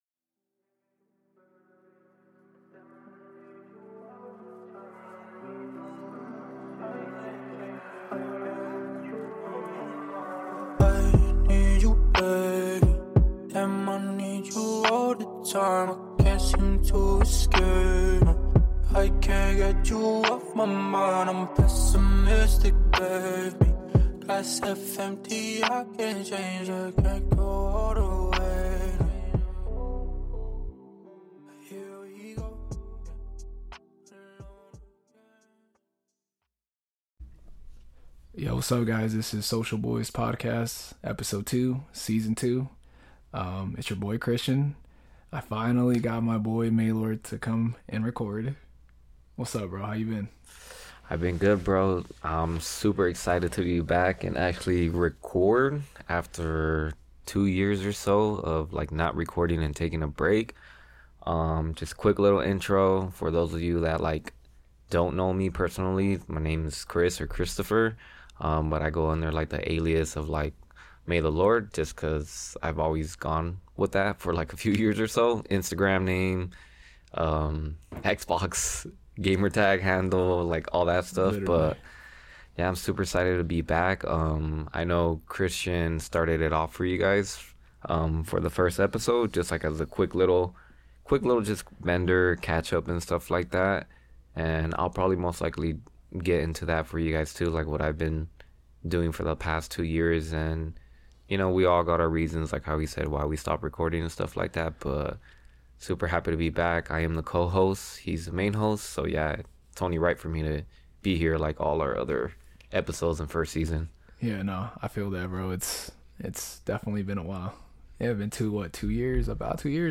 This podcast was created at "Cobra Arcade Bar" chugging AMF's and playing Dragon Ball Z Fighters.
From mental struggles to growth, from laughter to heavy truths, this is a conversation about resilience, vulnerability, and figuring out life one step at a time.